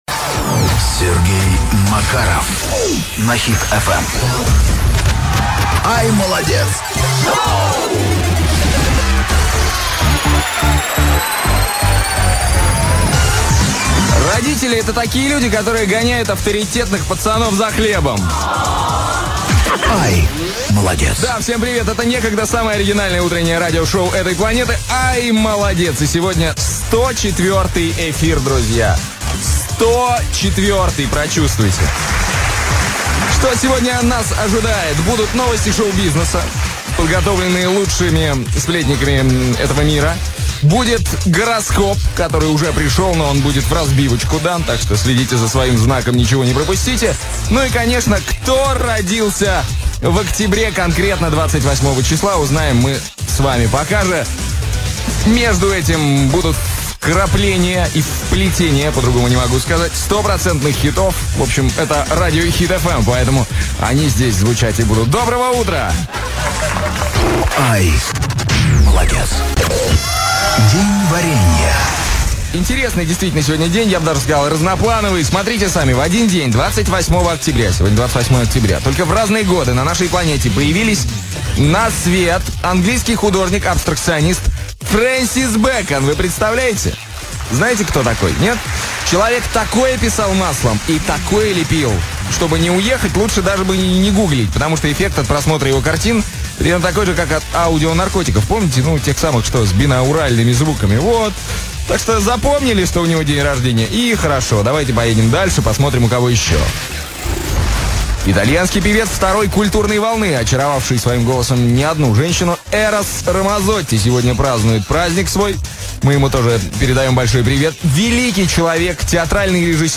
Последний выпуск iмолодец шоу на Хит FM. Запись эфира.
утреннее шоу